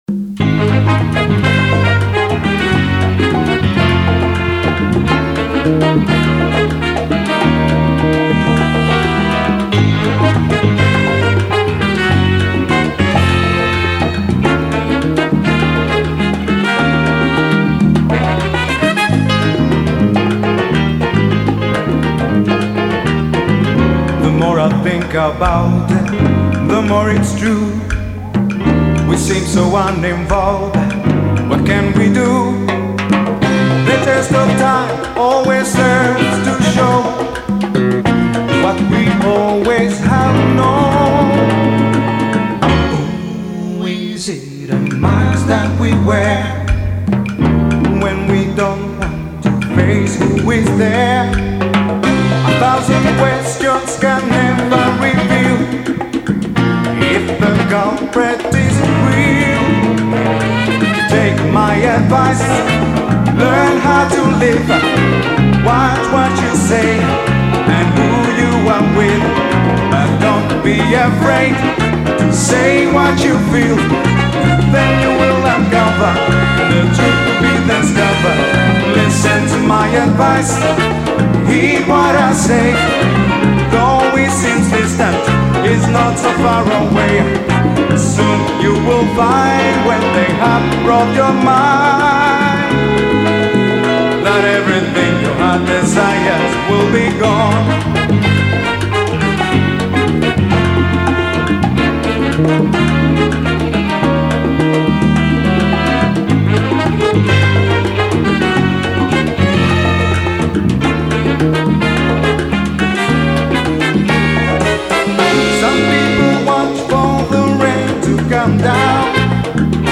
salsa band